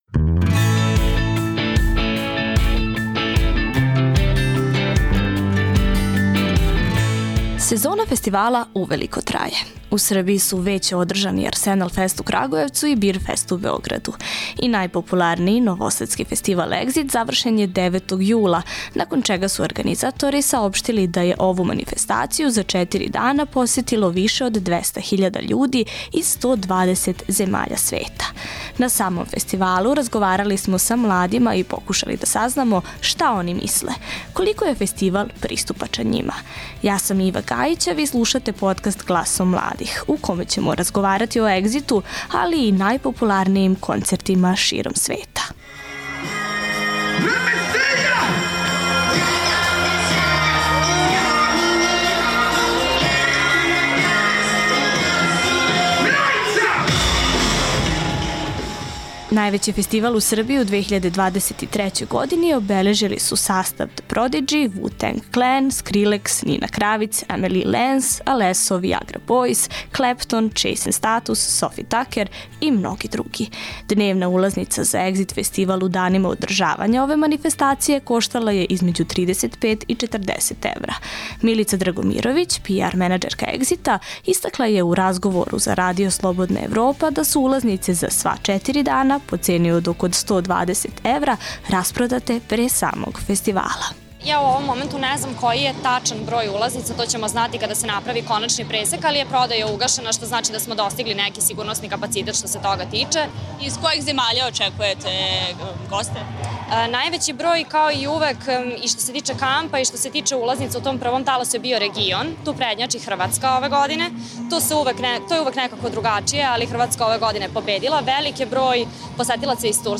I najpopularnijii novosadski festival Exit završen je 9. jula, nakon čega su organizatori saopštili da je ovu manifestaciju za četiri dana posetilo više od 200.000 ljudi iz 120 zemalja sveta. U novoj epizodi podkasta razgovarali smo sa mladima i pokušali da saznamo šta oni misle - koliko je ovaj festival pristupačan.